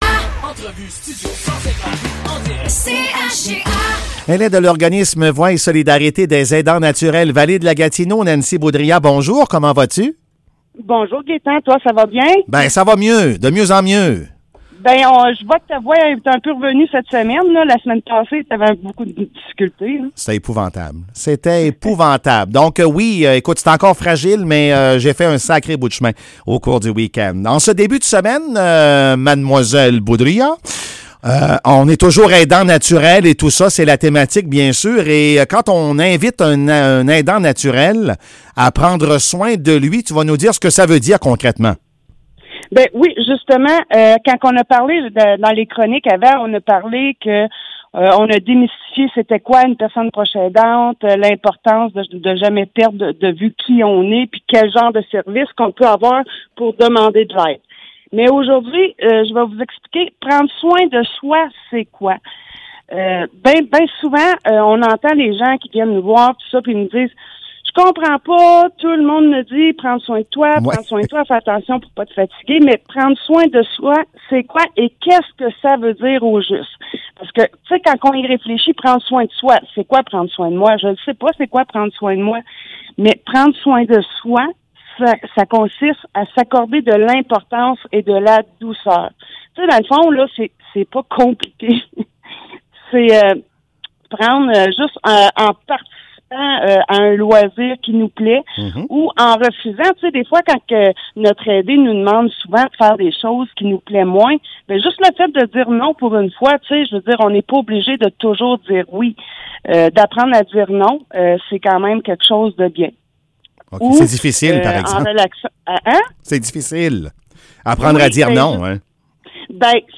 Chronique de Voix et solidarité des aidants naturels de la Vallée-de-la-Gatineau